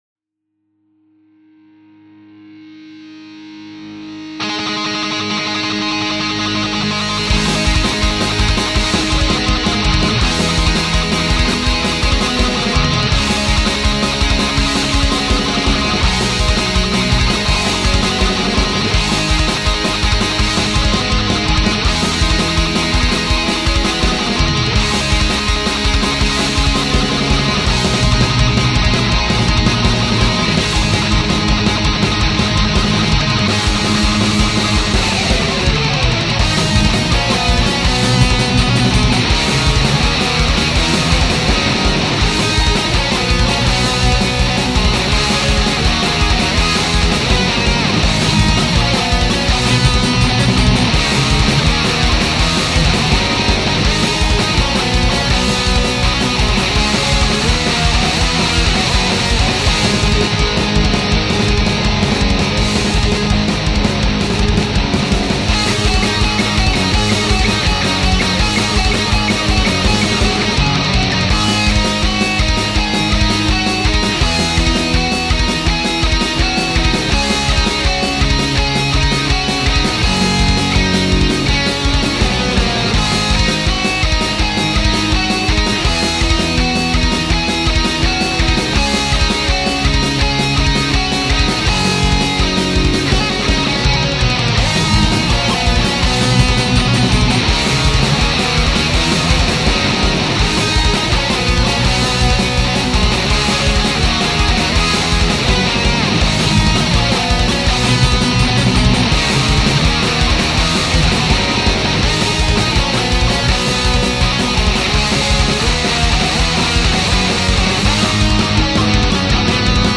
That breakdown at the end is just the cherry on top...
Punk